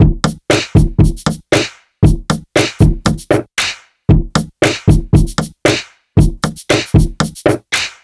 Baby Drums Loop.wav